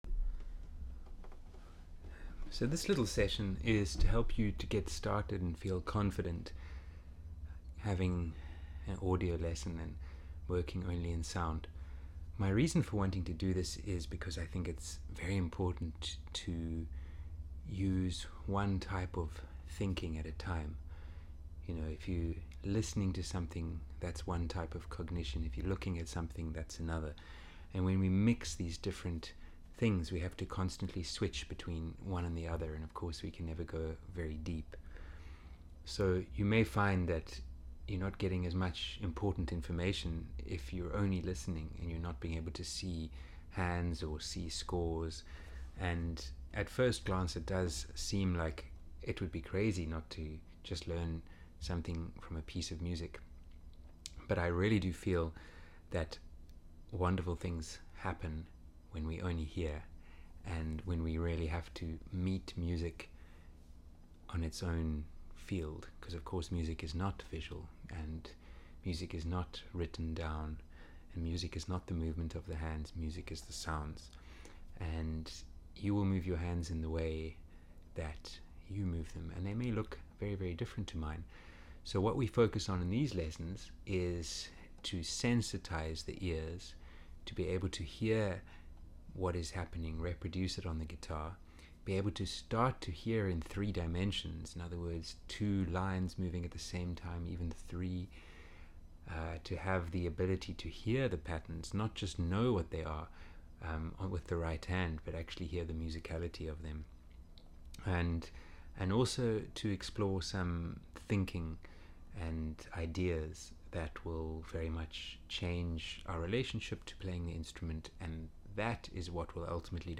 AUDIO LESSON PRIMER